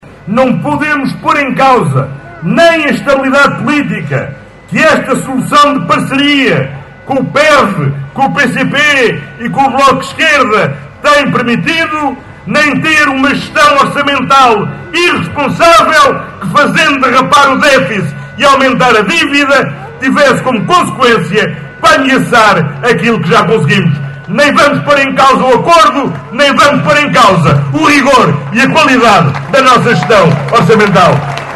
Os socialistas rumaram a norte no passado sábado (25 de agosto) para a habitual “rentrée” política que este ano teve lugar no Parque Municipal em Caminha.